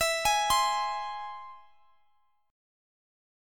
E+ Chord
Listen to E+ strummed